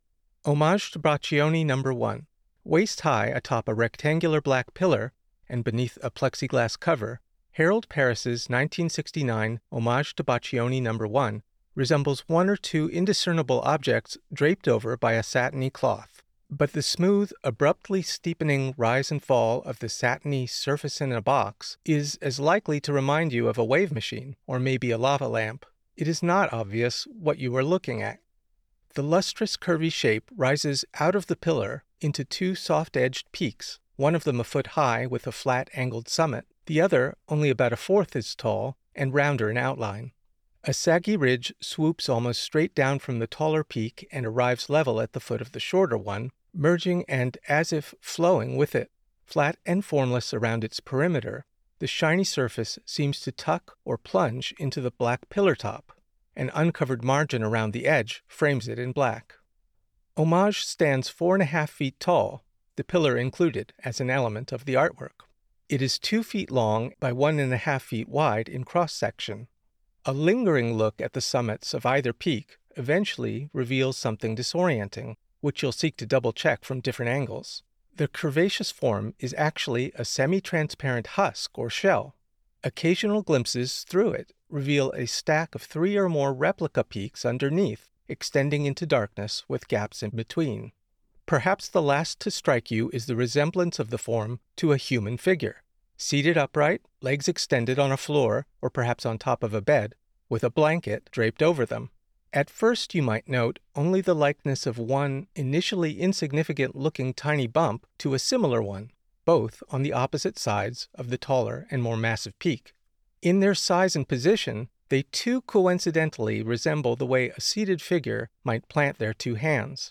Audio Description (02:56)